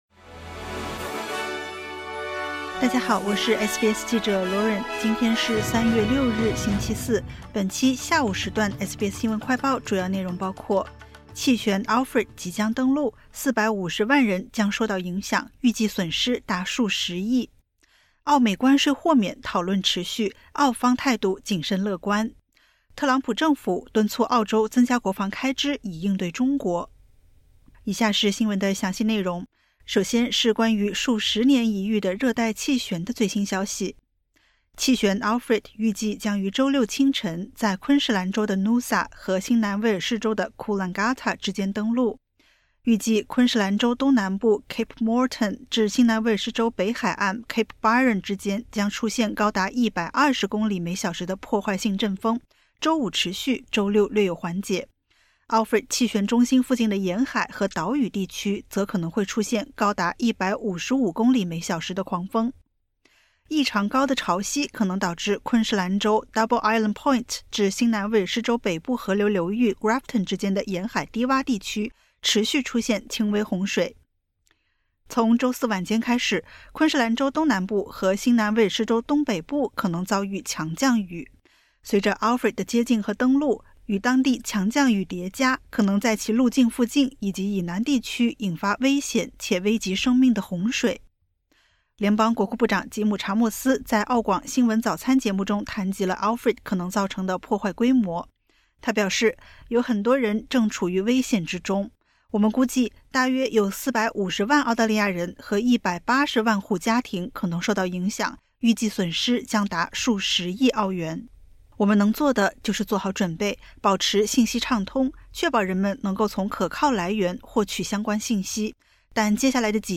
【SBS新闻快报】Alfred即将登陆 450万人受影响 预计损失达数十亿